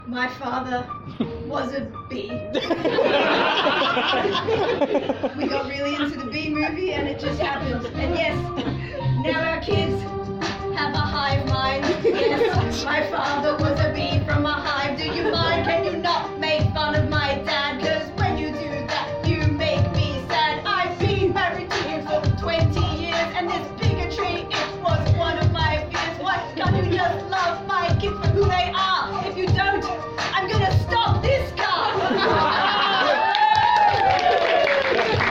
Tags: rap